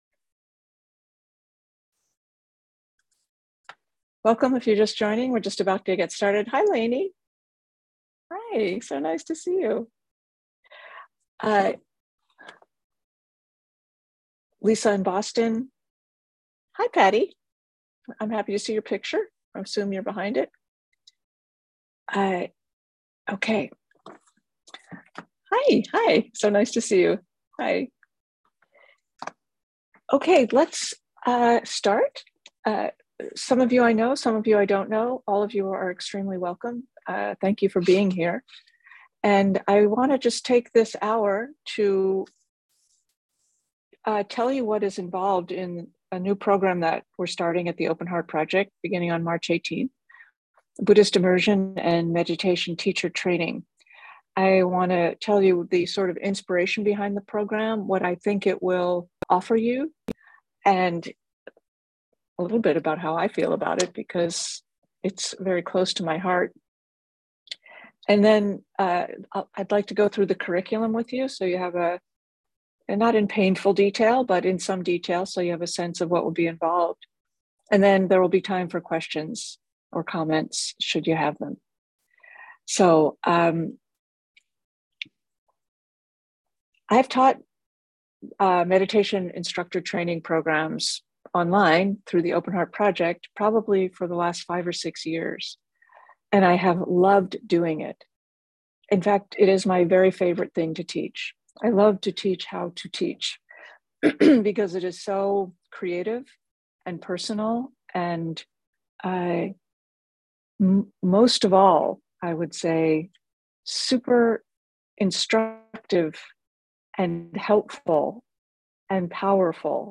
Buddhist Immersion and Meditation Teacher Training info session